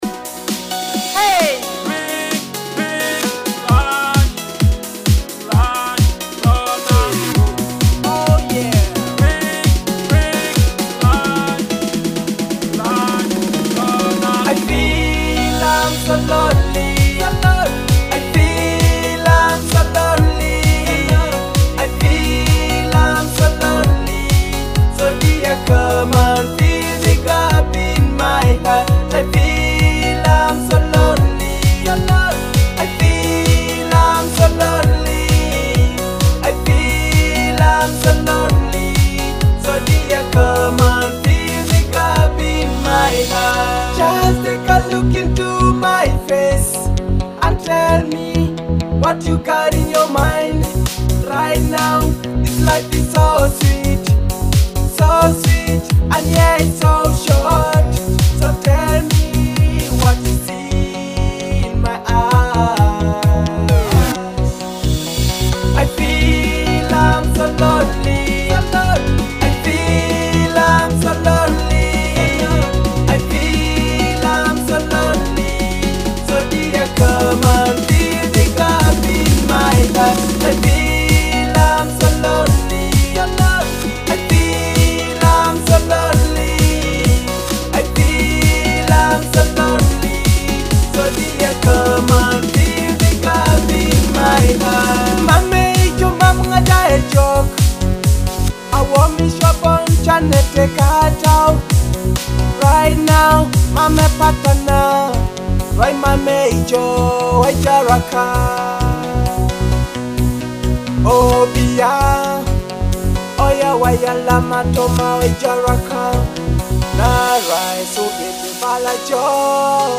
a heartfelt love ballad with soulful vocals.